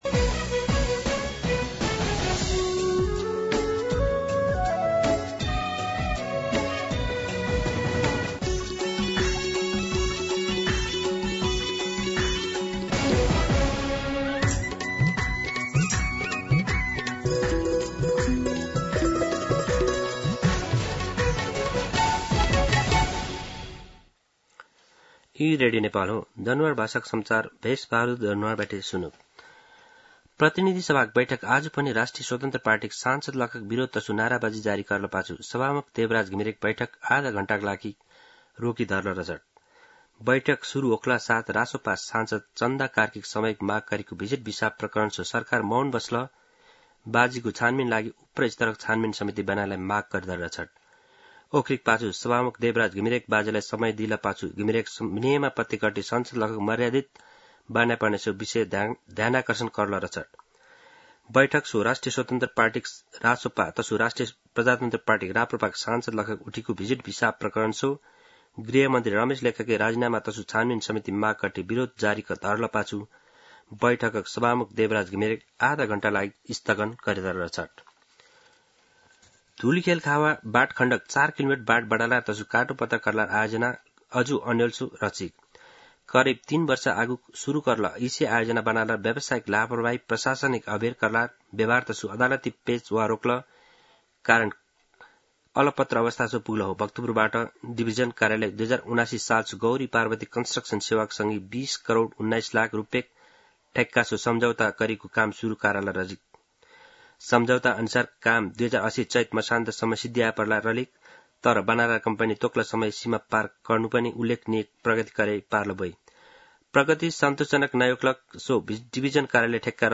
दनुवार भाषामा समाचार : ३ असार , २०८२
Danewar-News-03-3.mp3